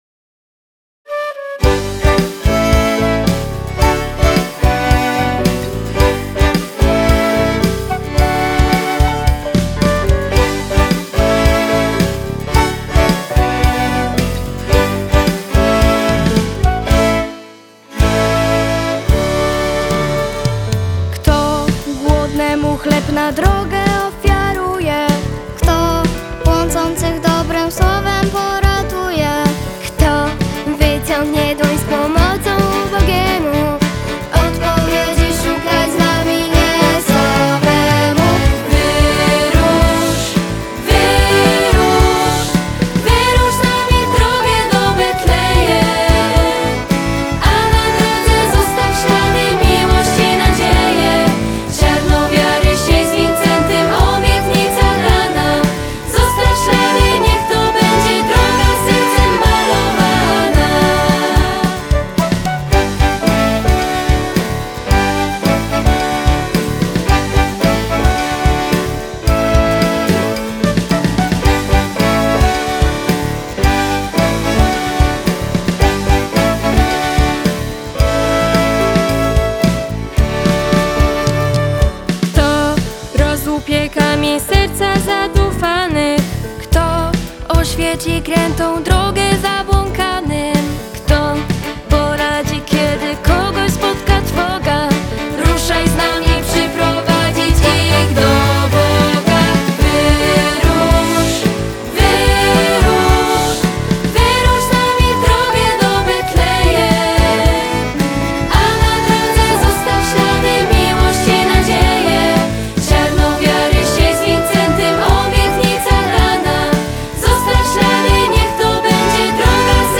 Wersja z wokalem